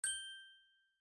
دانلود آهنگ چشمک زدن 1 از افکت صوتی انسان و موجودات زنده
جلوه های صوتی
دانلود صدای چشمک زدن 1 از ساعد نیوز با لینک مستقیم و کیفیت بالا